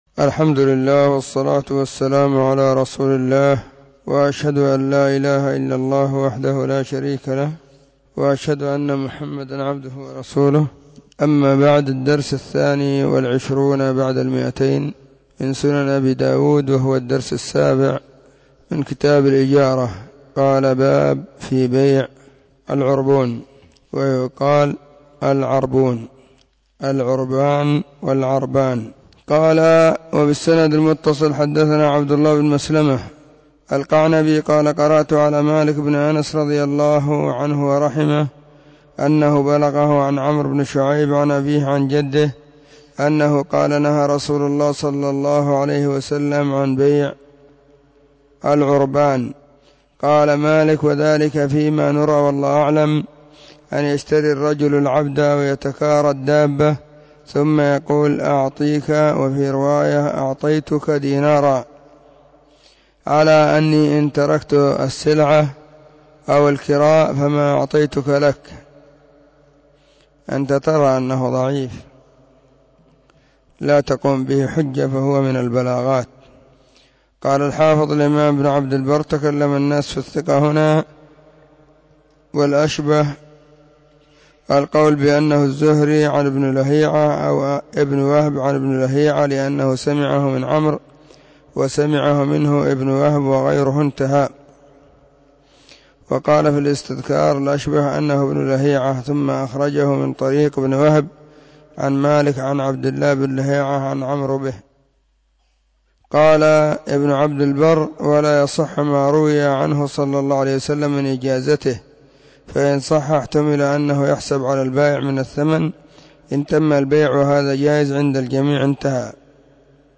🕐 [بعد صلاة العصر في كل يوم الجمعة والسبت]